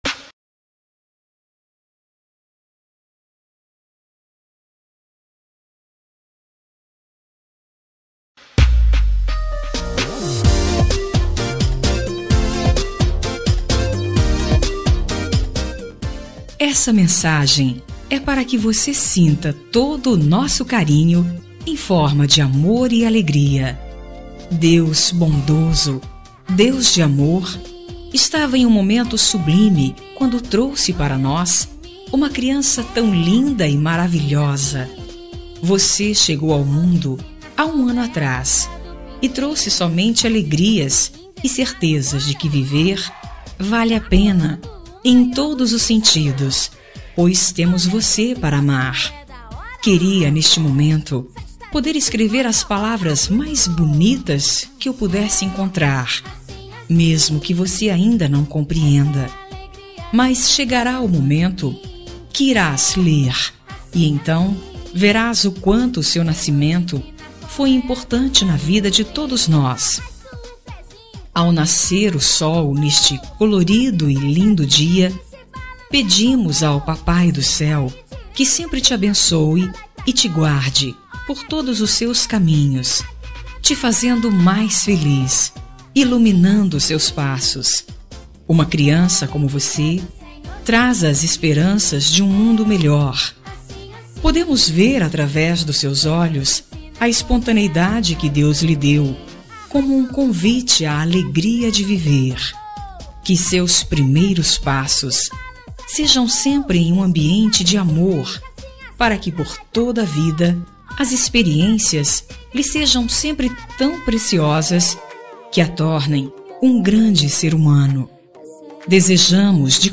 Telemensagem de Criança – Voz Feminina – Cód: 8124 – Legal